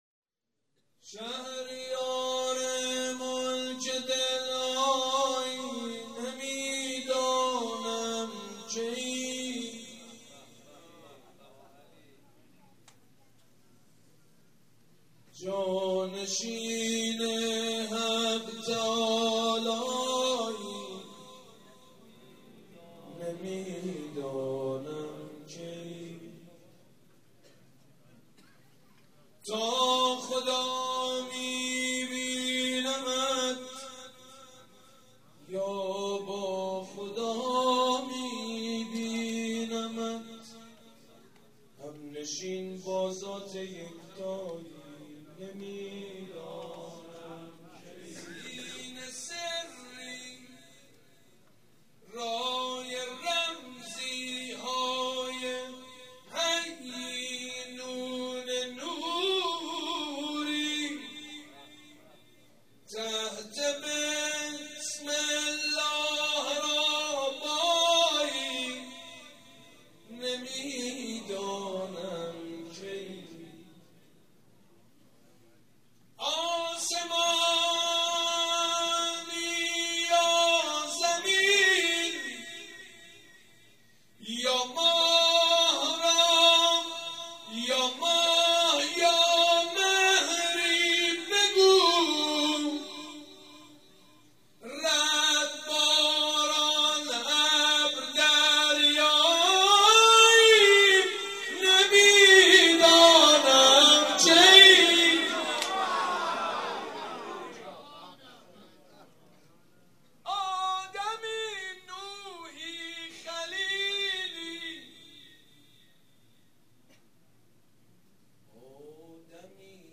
مناسبت : ولادت امیرالمومنین حضرت علی علیه‌السلام
مداح : سیدمجید بنی‌فاطمه قالب : مدح شعر خوانی